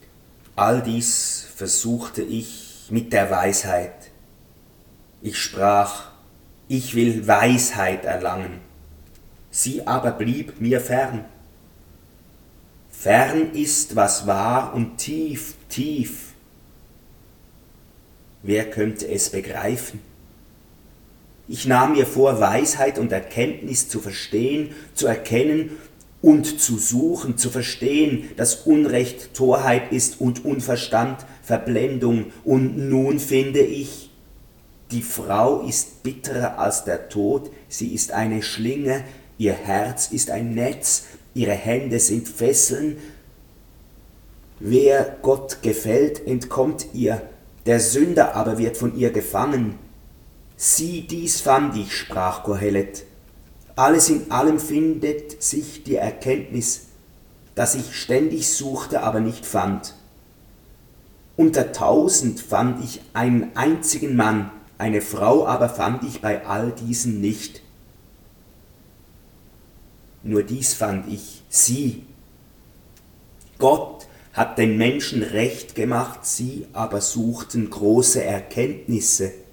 Durchgehende Lesung biblischer Bücher